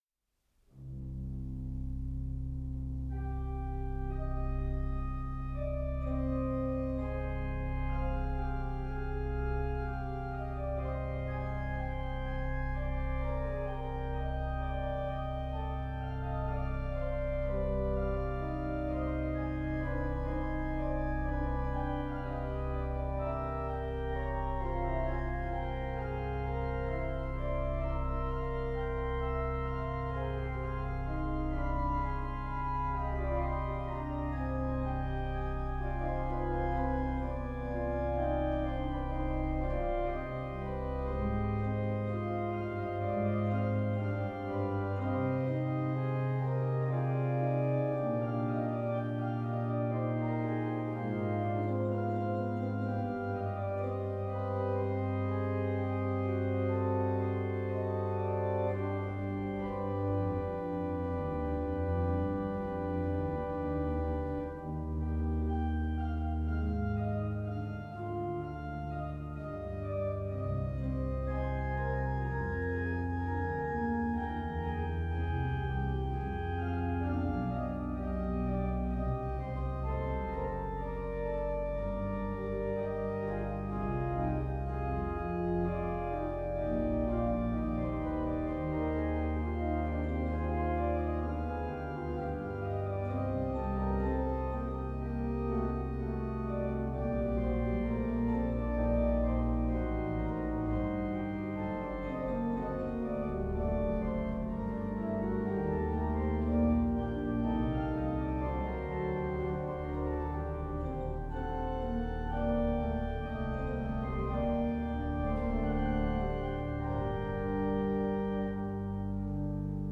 на Большом органе Рижского домского собора
Прелюдия и фуга до минор.